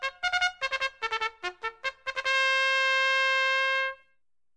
racestart.mp3